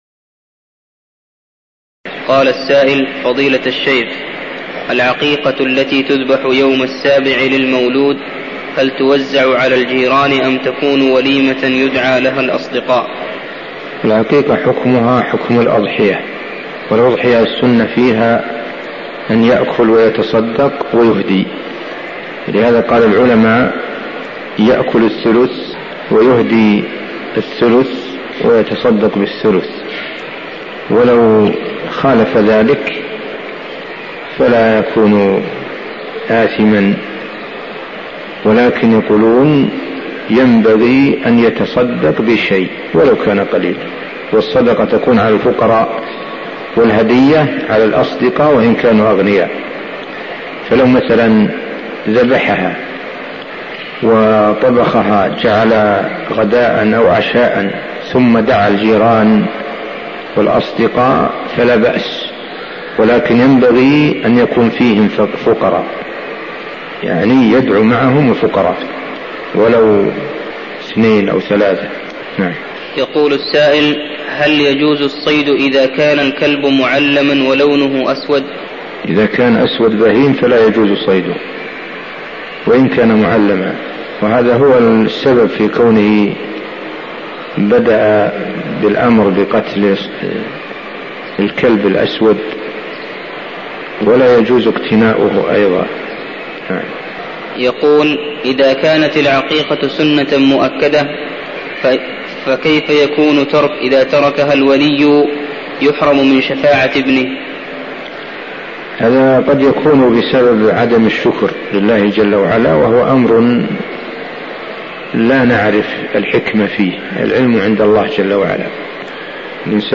تاريخ النشر ٢٧ جمادى الأولى ١٤١٤ هـ المكان: المسجد النبوي الشيخ